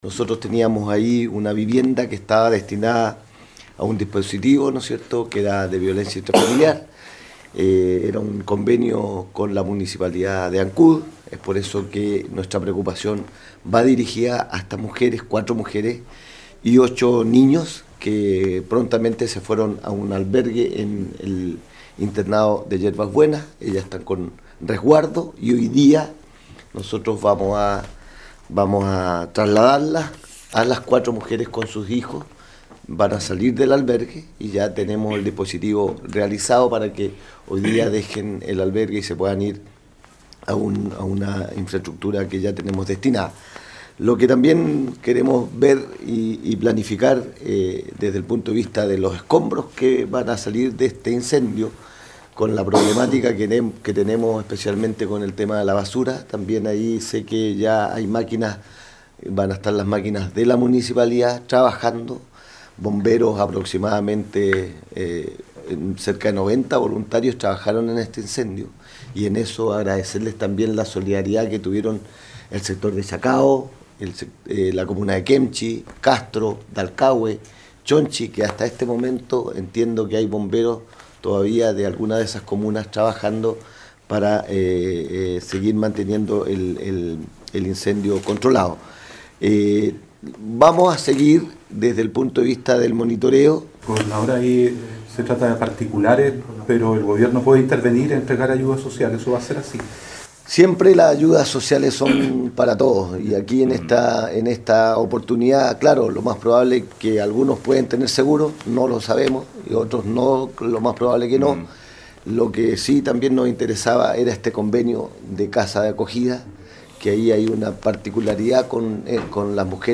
El gobernador provincial expresó que estas personas serán reubicadas.